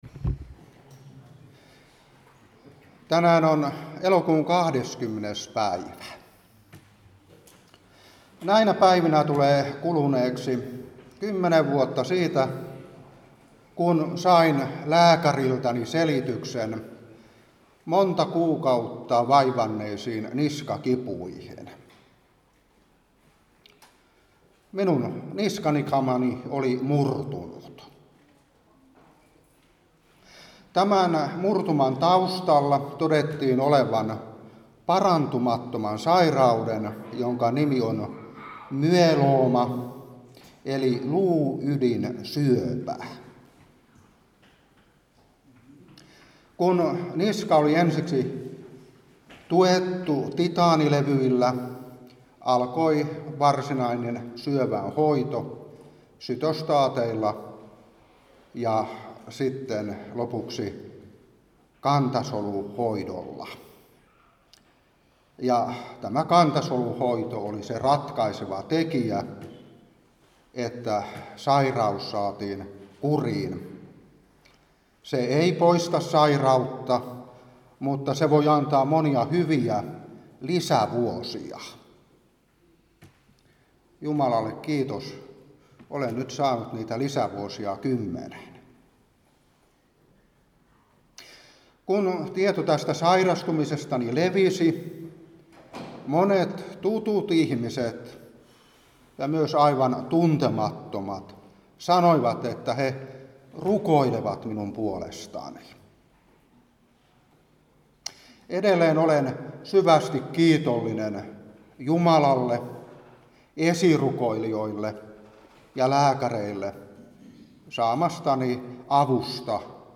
Seurapuhe 2023-8. Ps.6:3. Ps.51:10. Jes.53:3-5. SK 44:4.